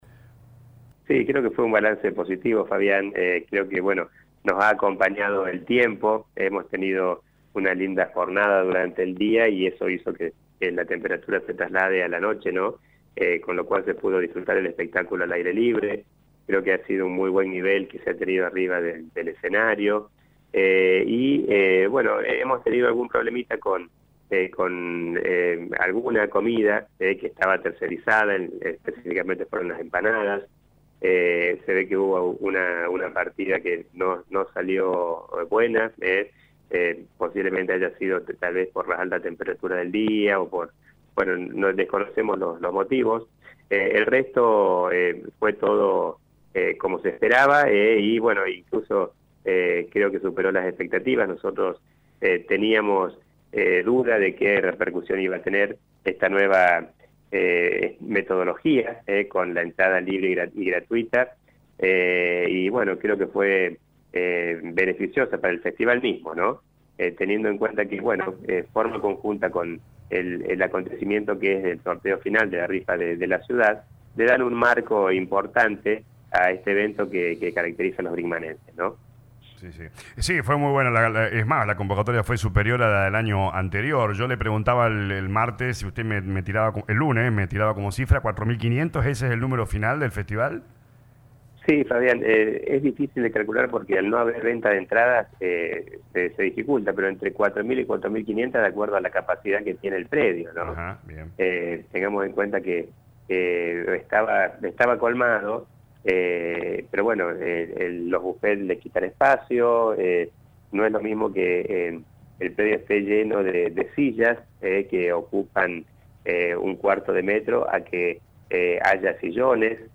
El Intendente de la ciudad dialogó con LA RADIO 102.9 para realizar un balance de la edición 16º del Festival Nacional del Humor y la Canción.